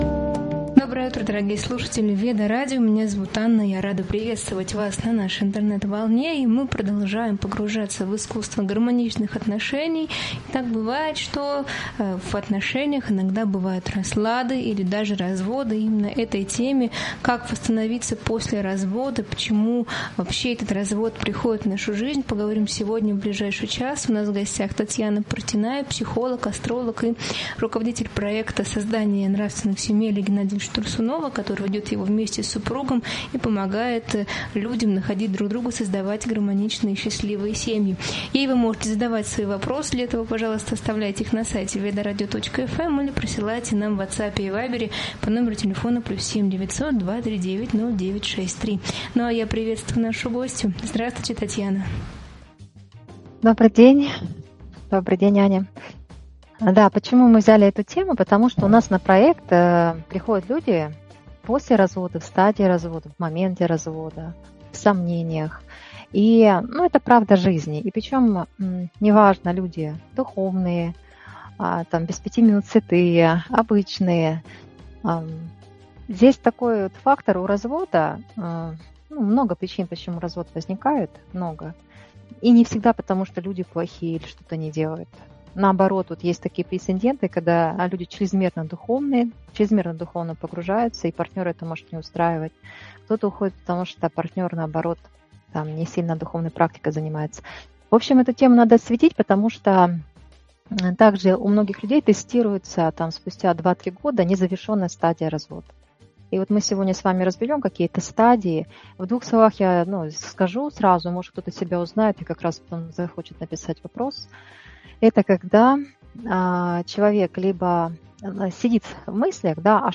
В эфире обсуждается, как духовная энергия помогает исцелять отношения и справляться с болью после разрыва. Говорится о значении молитвы, открытого общения и внутренней работы над самоценностью.